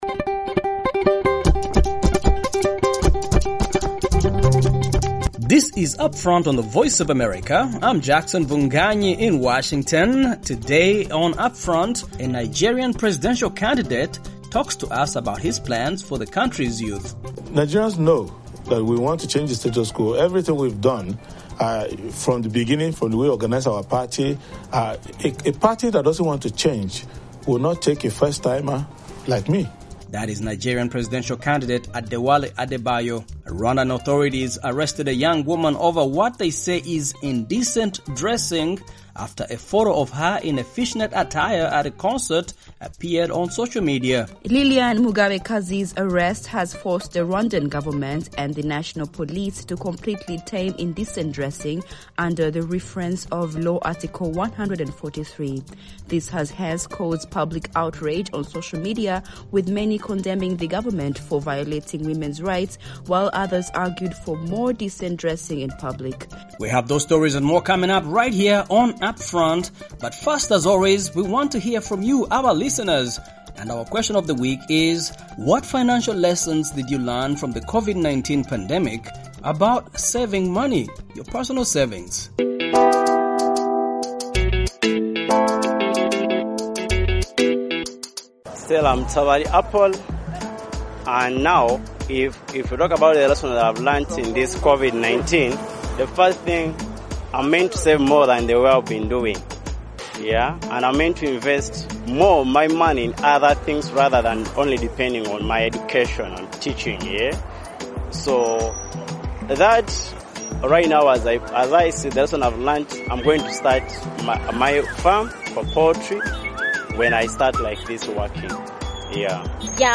talks to teens and young adults about politics, trends, lifestyles, health, entertainment, and other issues touching listeners’ lives